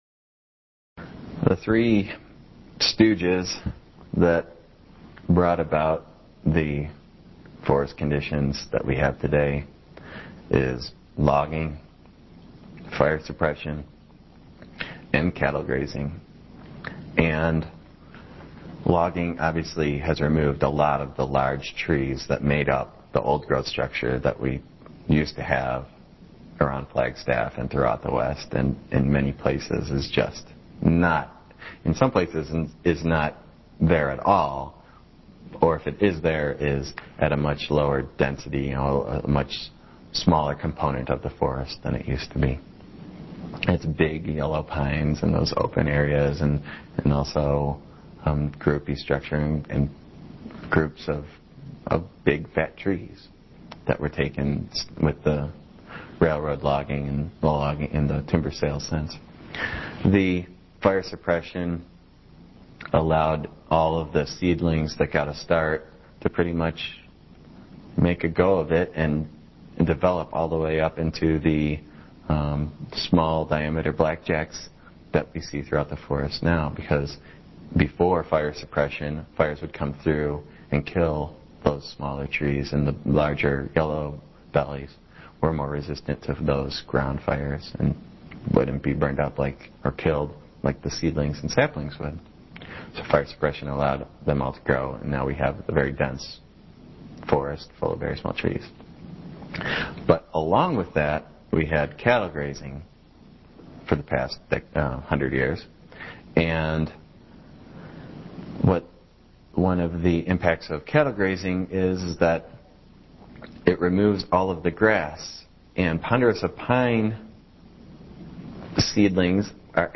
Hear interview excerpts